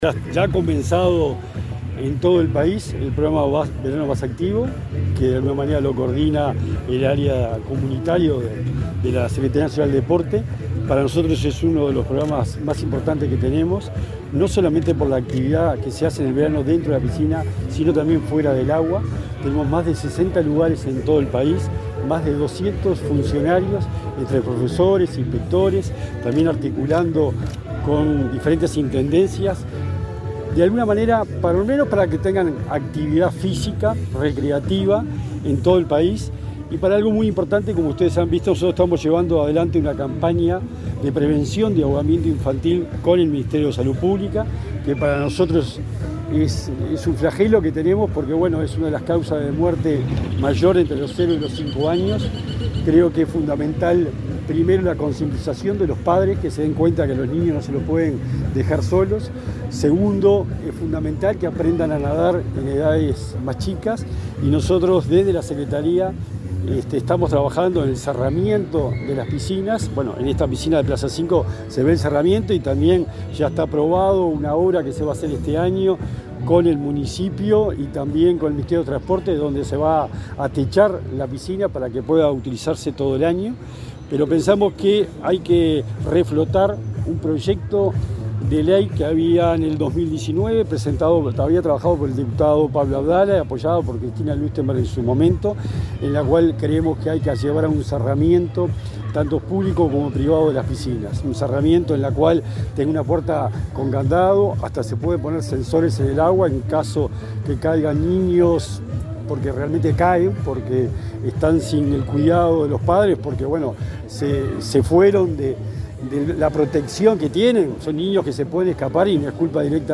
Declaraciones del secretario nacional del Deporte, Sebastián Bauzá
El secretario nacional del Deporte, Sebastián Bauzá, dialogó con la prensa, luego del lanzamiento, en la plaza n.° 5 de Montevideo, del programa